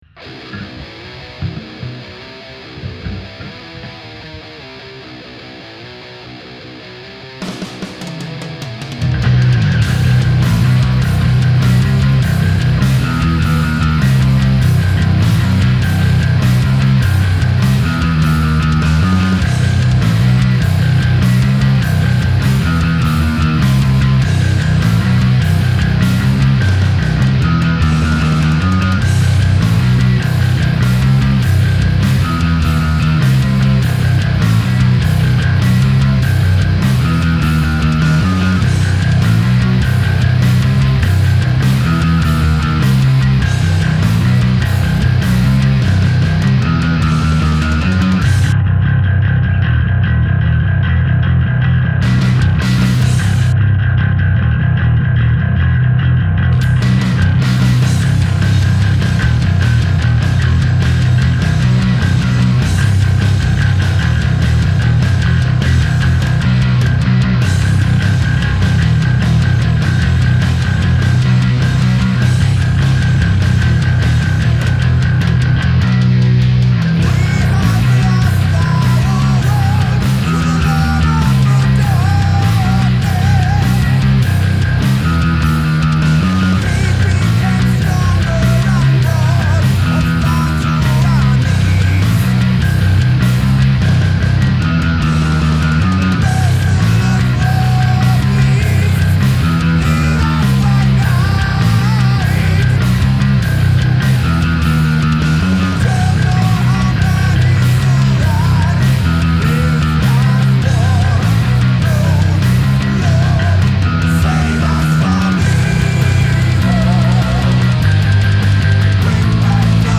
Hier ein Classic 5 mit JJ und BTS. Bässe und Höhen ca. 75% aufgedreht. Balance leicht zum Neck PU.
Saiten waren Rotosounds Steel drauf. Also zu wenig Höhen hatte der definitiv nicht. Gefällt mir generell sehr gut vom Sound, aber Saitenlage ab 3,5 mm letzter Bund B-Saite am schnarren.